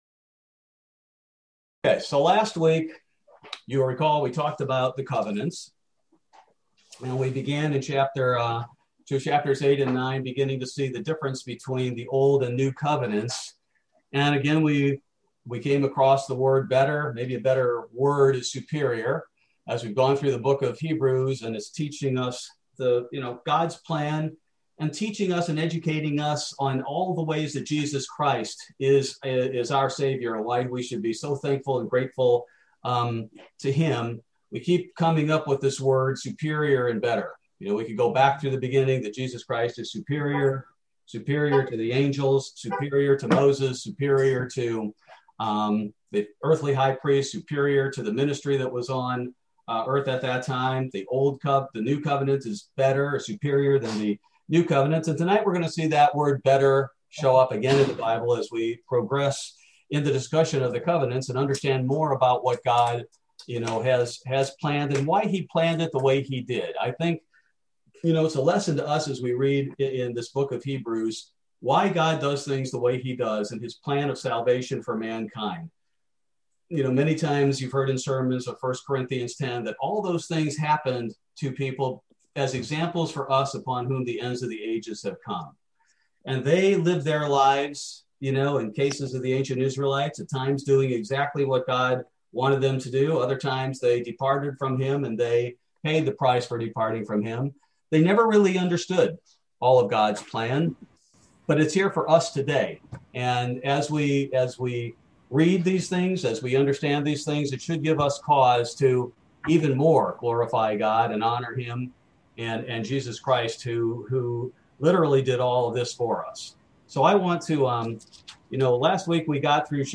Bible Study - January 13, 2021